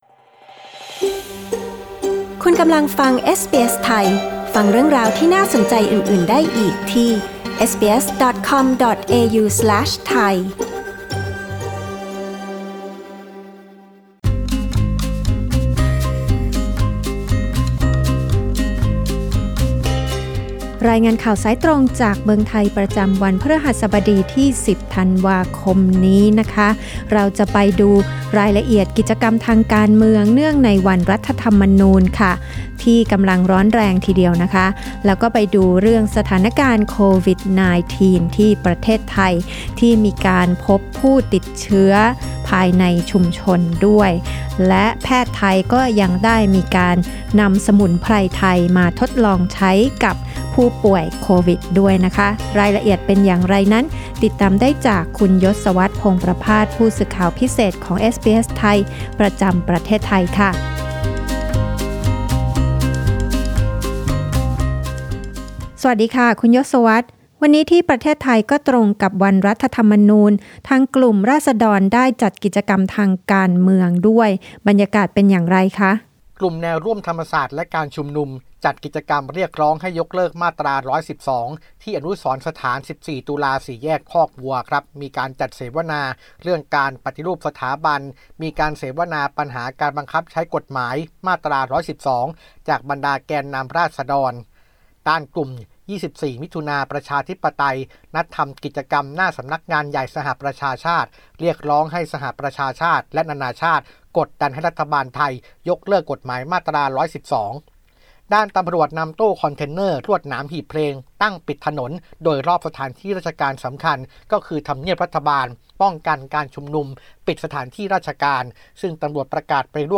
รายงานข่าวสายตรงจากเมืองไทย โดยเอสบีเอส ไทย Source: Pixabay